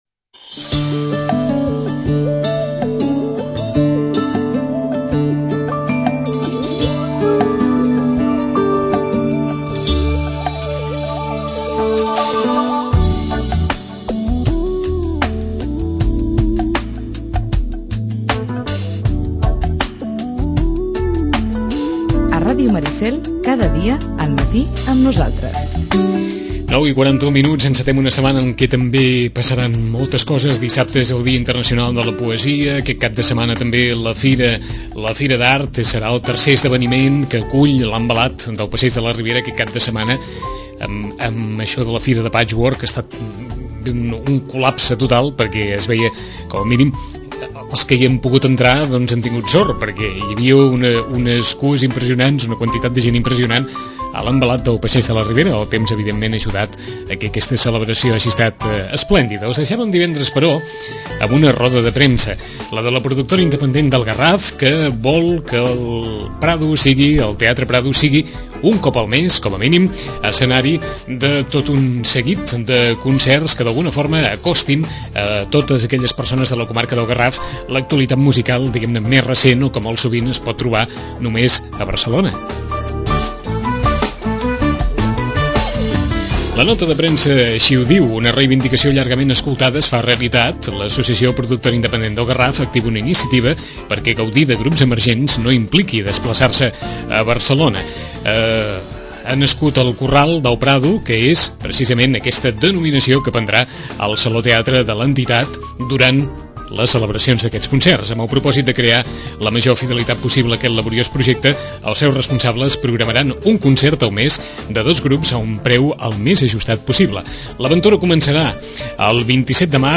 Roda de premsa de presentació de la iniciativa “El corral del Prado”, que oferirà mensualment, al Saló Teatre de la entitat, un concert amb dos grups emergents de pop-rock a preus populars.